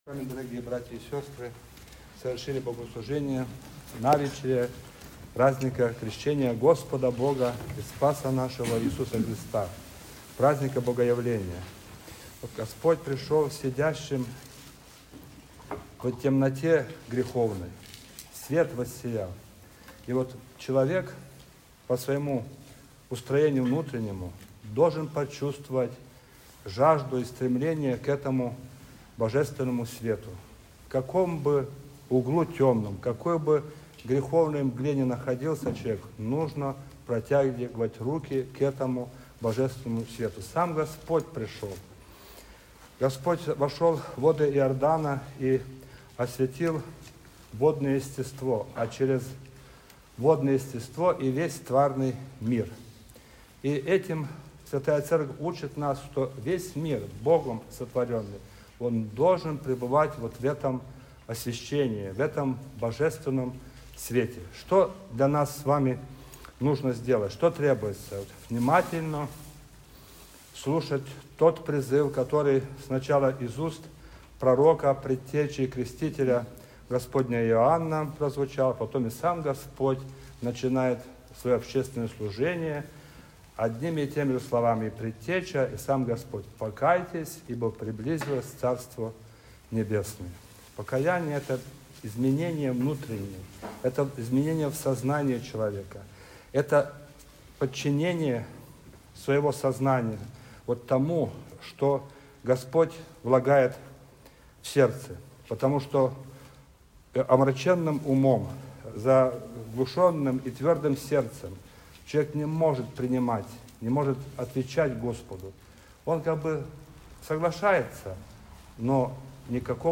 Слово